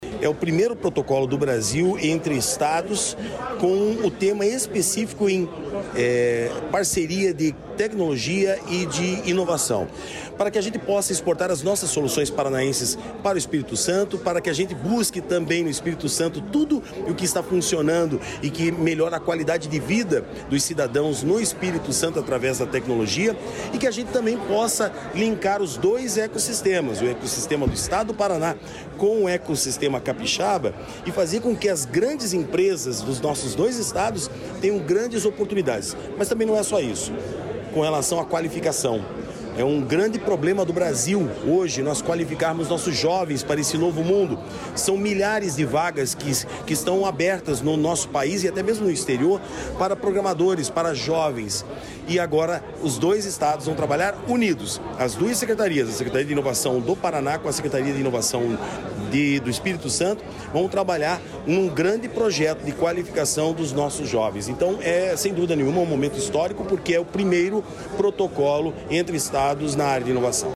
Sonora do secretário estadual da Inovação, Modernização e Transformação Digital, Marcelo Rangel, sobre a parceria entre o Paraná e o Espírito Santo